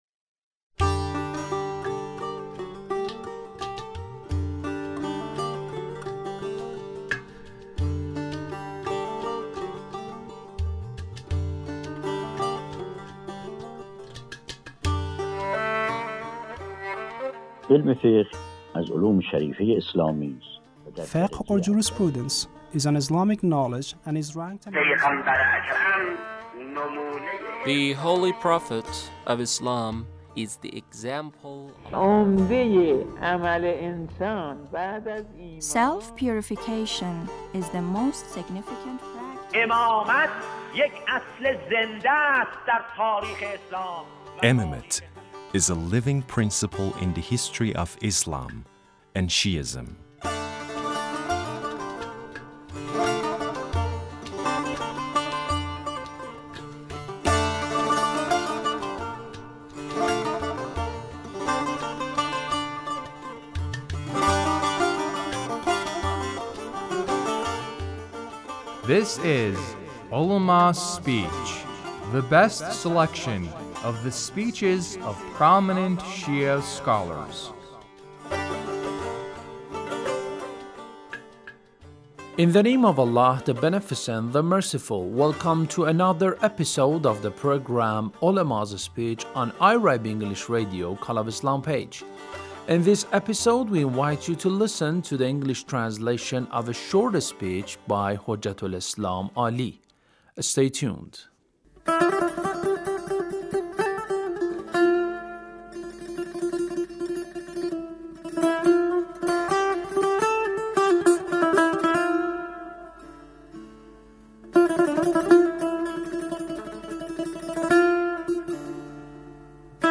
Ulemas' Speech (1133)